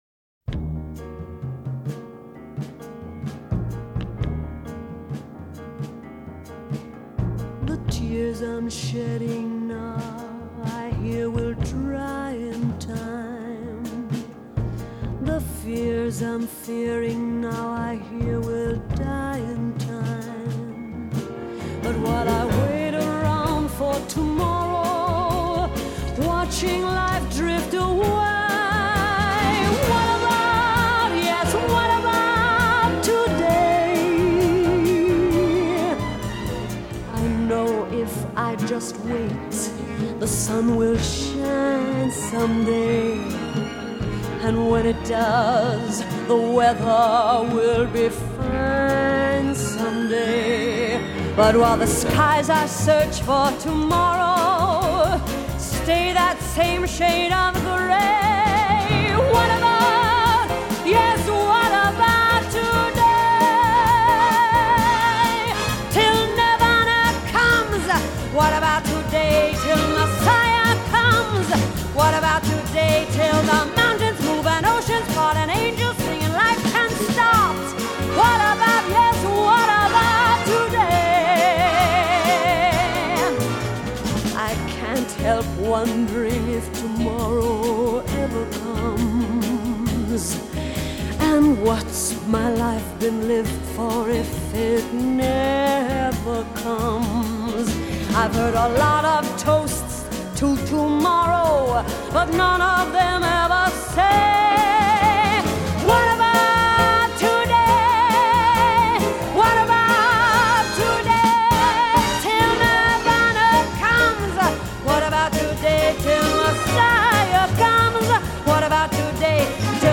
13 Over-the-Top Vocal Performances of The 1960s